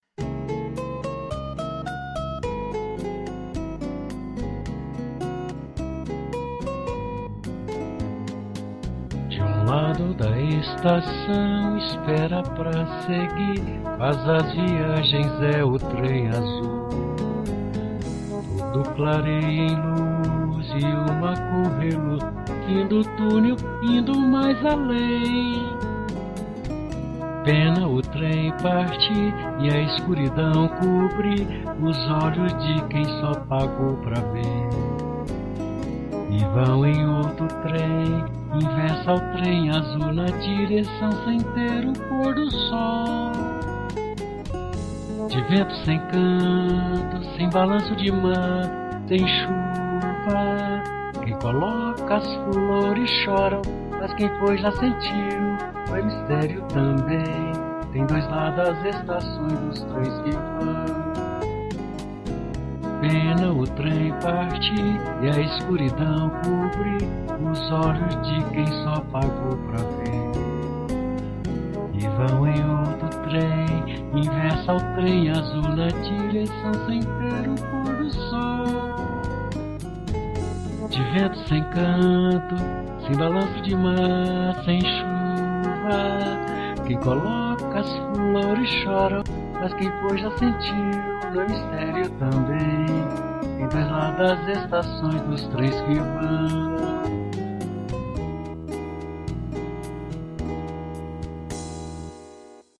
EstiloBossa Nova